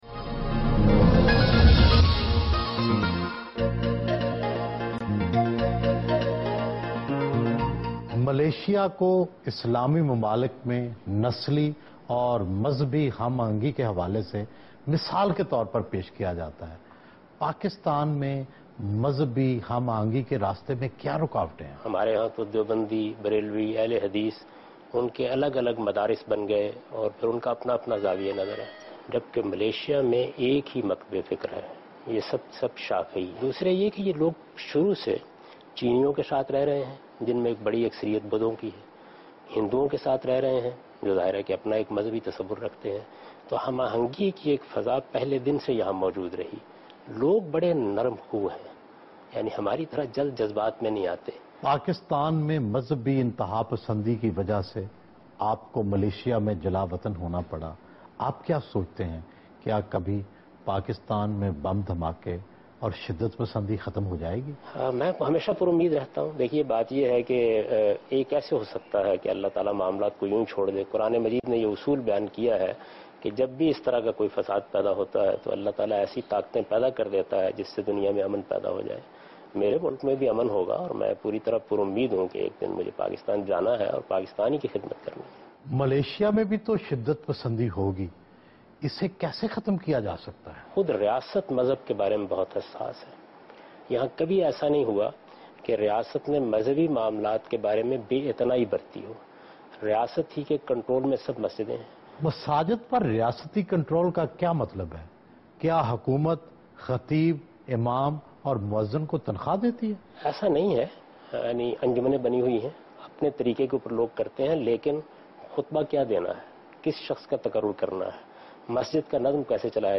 Category: TV Programs / Geo Tv / Questions_Answers /
Q & A session with Javed Ahmad Ghamidi in Geo TV program "Ek Din Geo ke Saath".
جیو ٹی وی کے پروگرام ایک دن جیو کے ساتھ میں جاوید احمد صاحب غامدی ملائشیا کی ترقی کے بارے میں ایک سوال کا جواب دے رہے ہیں۔